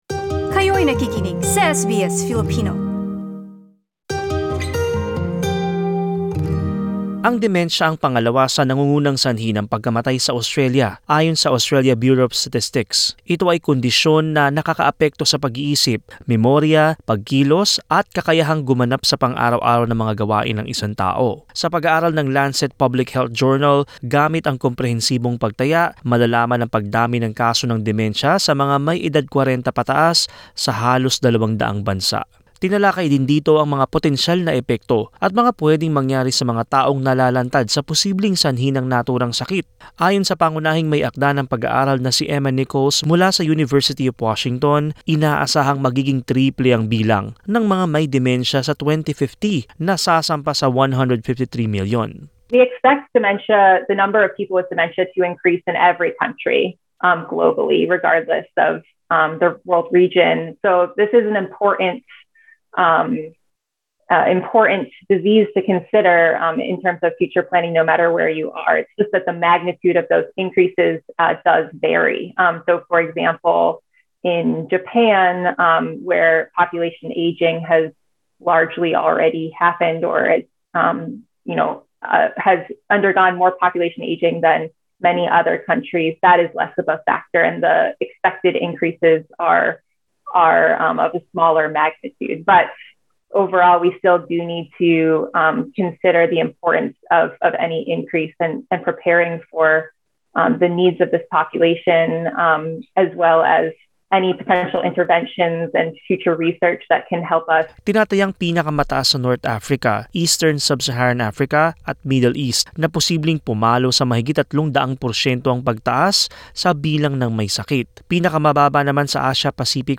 Pakinggan ang buong ulat: LISTEN TO Bilang ng may dementia sa buong mundo, posibleng umabot sa mahigit 150 milyon SBS Filipino 05:55 Filipino Share